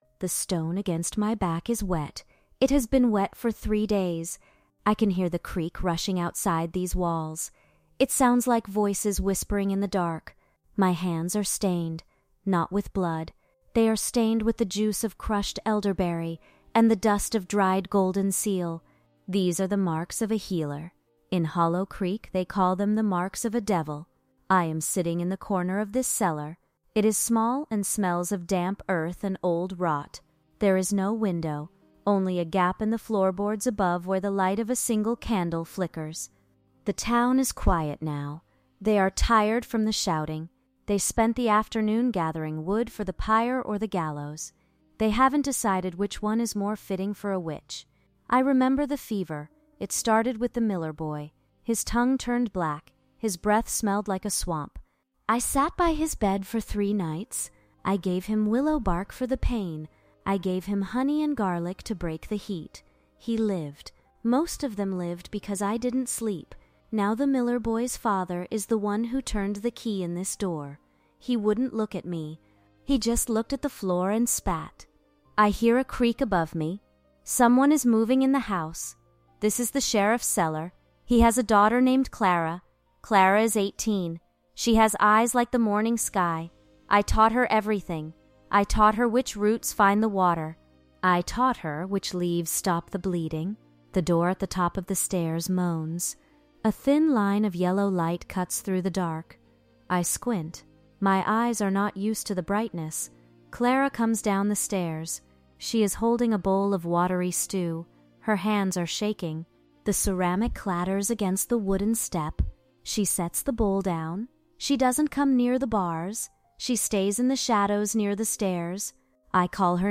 This script is optimized for high-quality TTS narration, utilizing a grounded, intimate voice that avoids decorative prose to let the raw emotional weight of the events drive the experience.